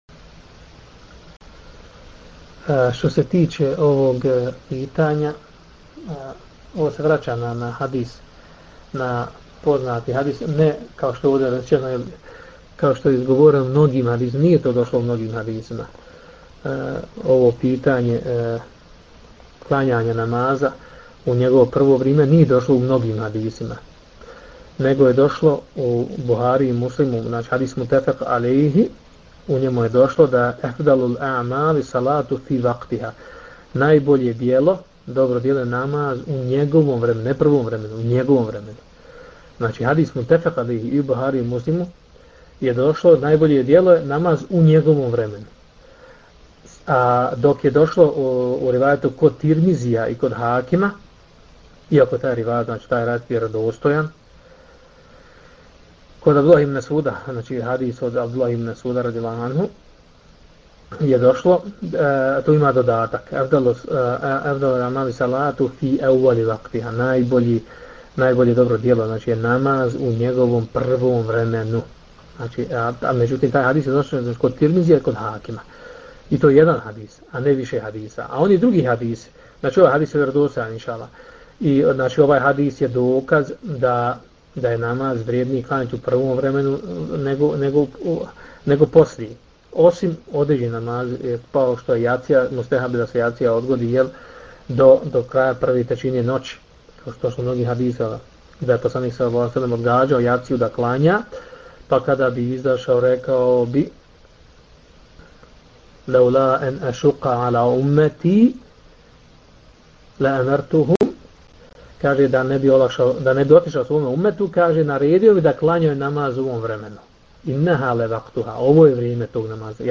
Poslušajte audio isječak iz predavanja